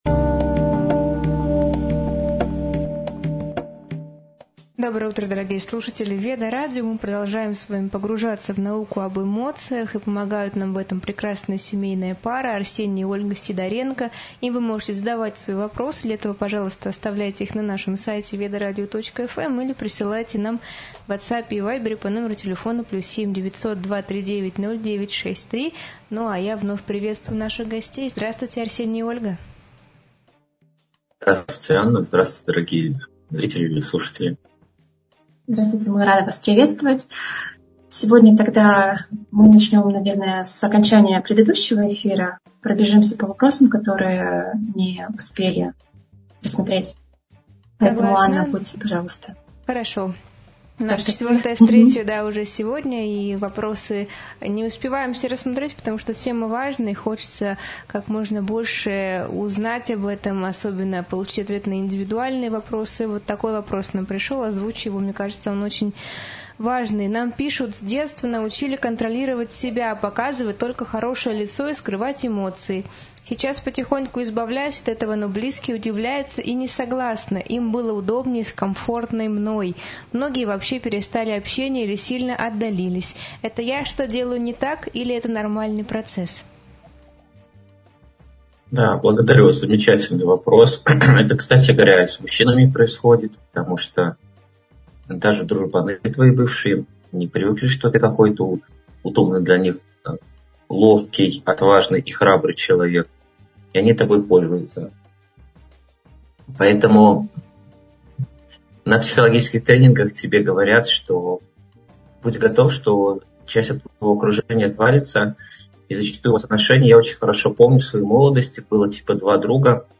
В эфире обсуждаются эмоции, их природа и влияние на восприятие, поведение и отношения. Затрагиваются различия мужской и женской психики, сложности коммуникации, роль осознанности и самоанализа.